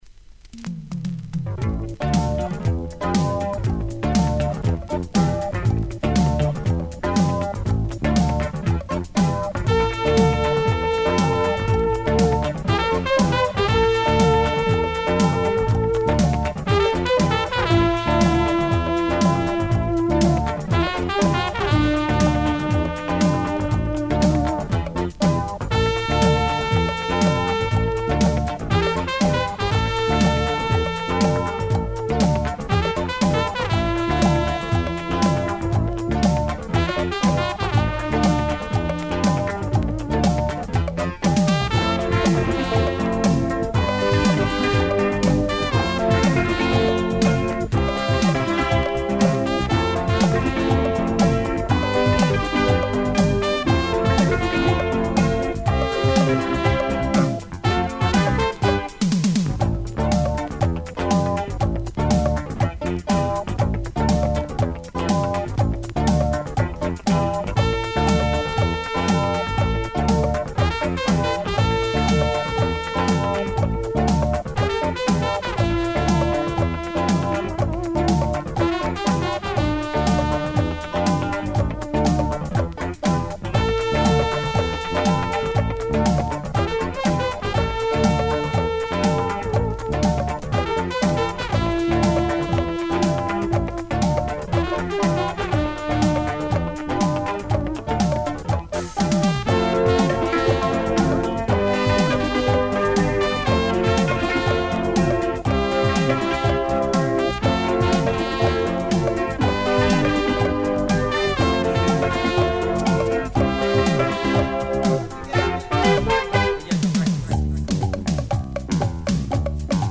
Great percussion heavy synth groover